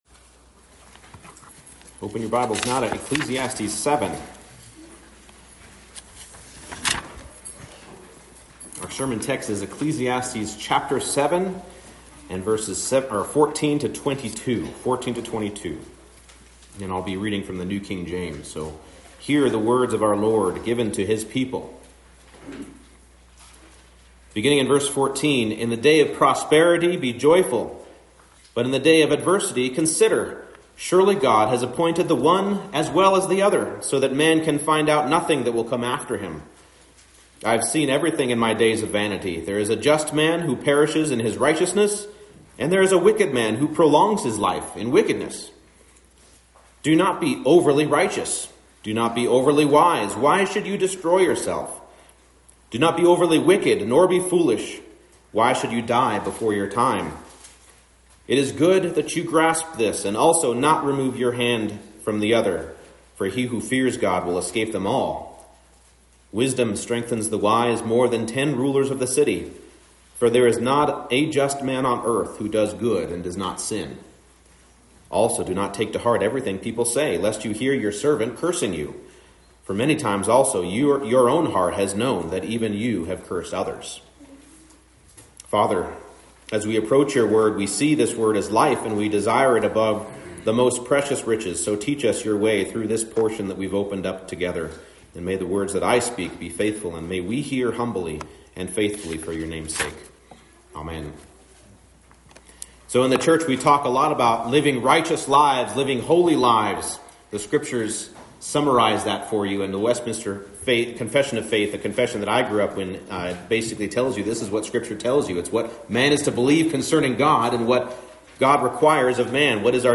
Ecclesiastes 7:14-22 Service Type: Morning Service The wise will not pursue righteousness for the sake of itself